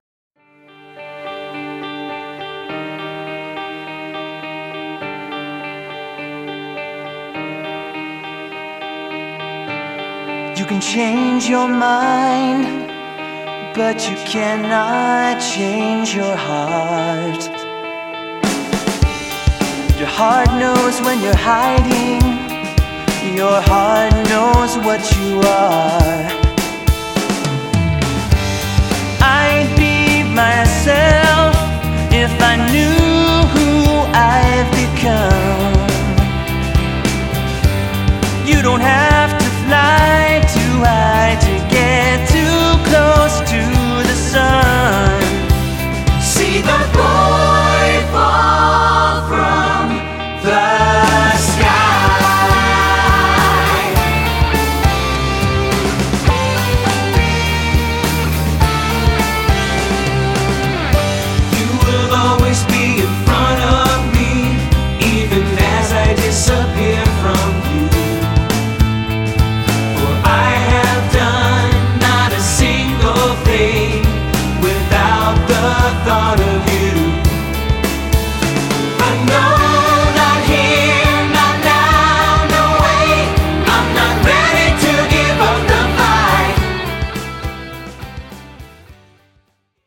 Voicing: 2-Part or SSA